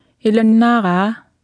Below you can try out the text-to-speech system Martha.
Speech synthesis Martha to computer or mobile phone
Speech Synthesis Martha